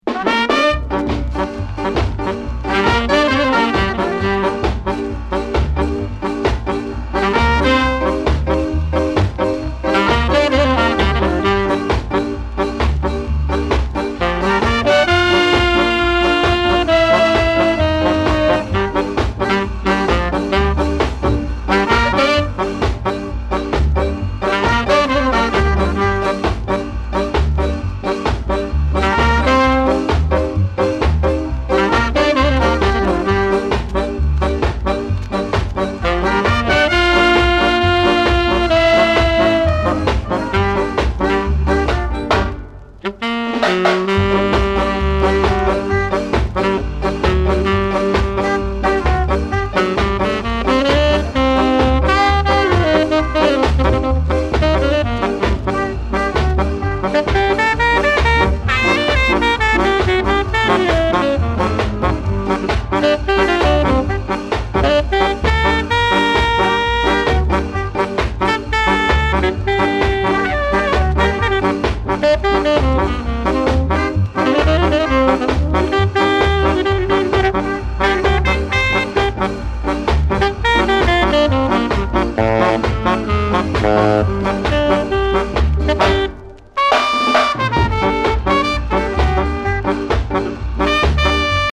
スカ・レゲエ
60年代中期のヴィンテージ感がたまらんです。